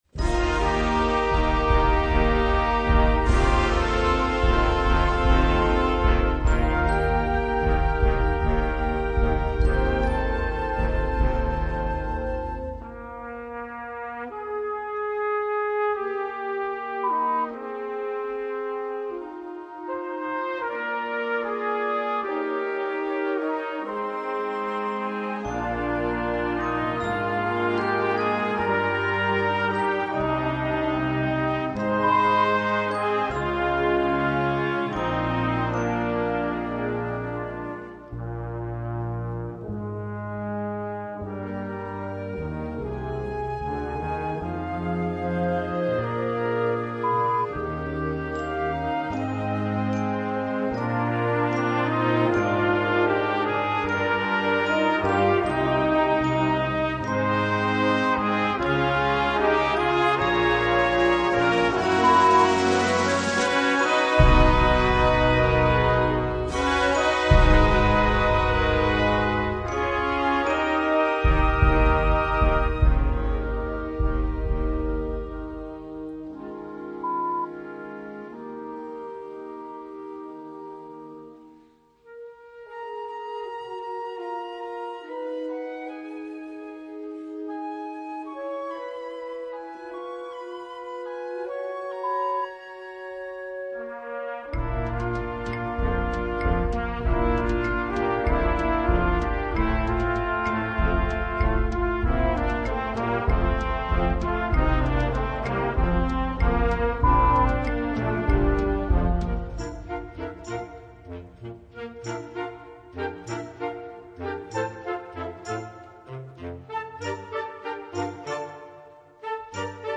Gattung: Aus dem Musical
Besetzung: Blasorchester